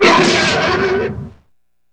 Index of /90_sSampleCDs/E-MU Producer Series Vol. 3 – Hollywood Sound Effects/Water/Alligators